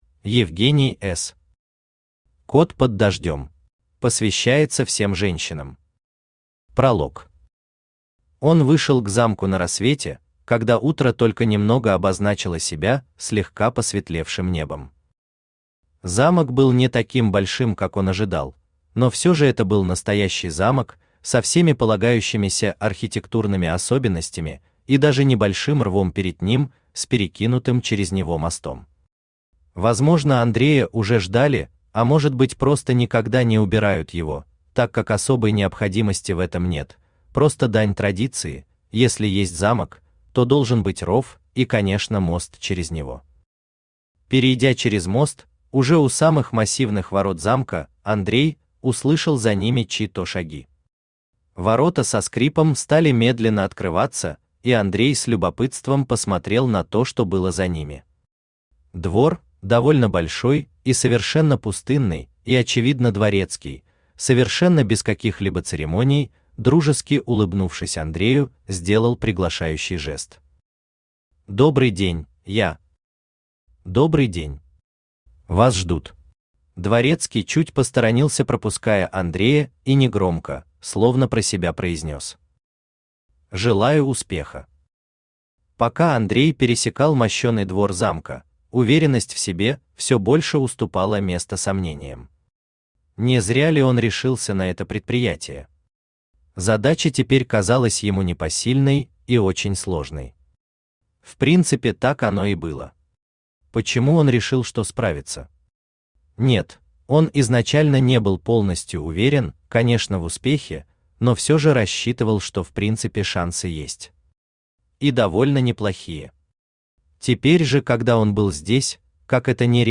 Аудиокнига Кот под дождём | Библиотека аудиокниг
Aудиокнига Кот под дождём Автор Евгений С. Читает аудиокнигу Авточтец ЛитРес.